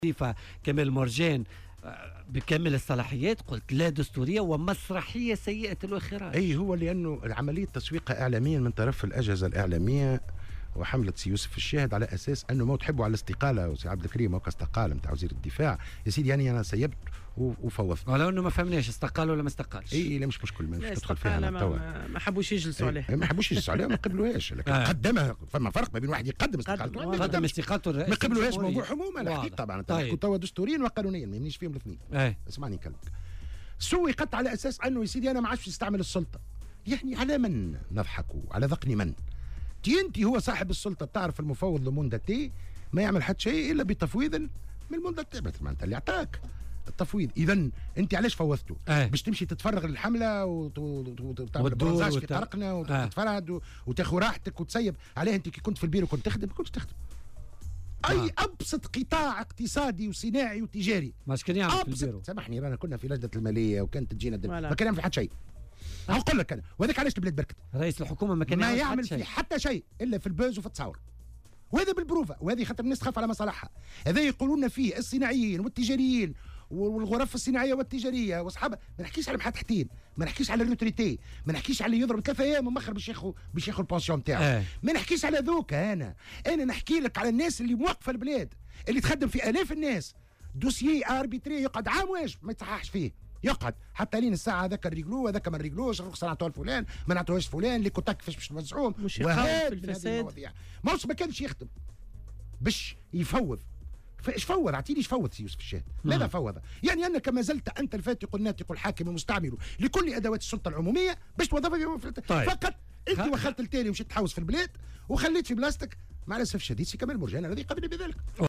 وقال في مداخلة له اليوم في برنامج "بوليتيكا" إن الأمر قد سُوّق على أن الهدف منه عدم استعمال السلطة وهو ما وصفه بـ"الضحك على الذقون"، وفق تعبيره.